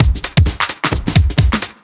OTG_TripSwingMixB_130b.wav